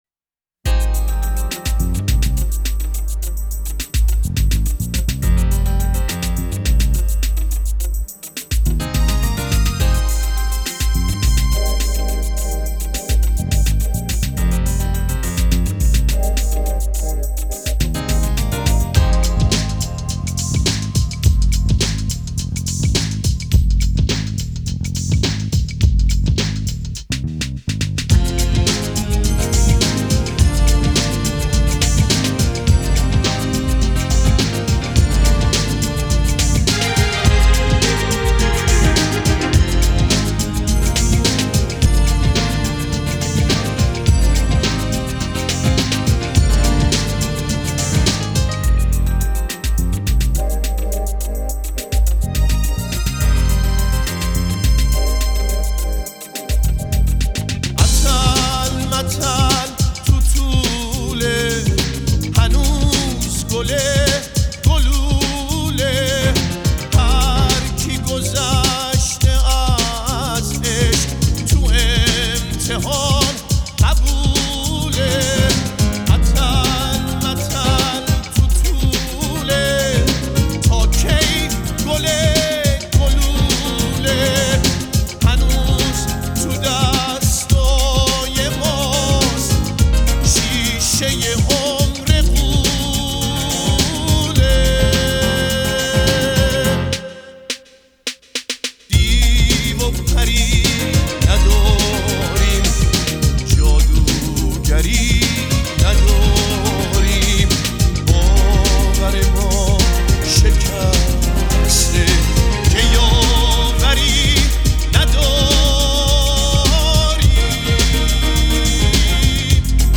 آهنگ غمگین آهنگ قدیمی